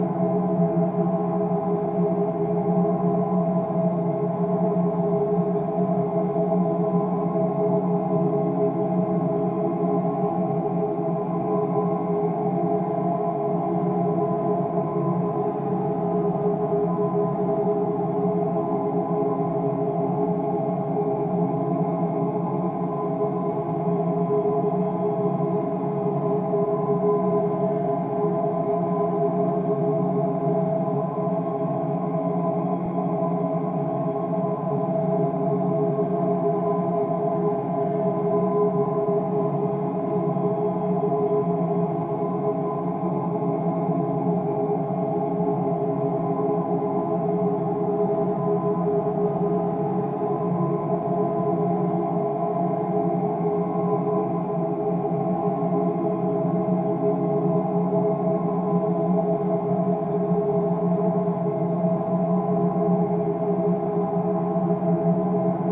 made some (probably placeholder) drones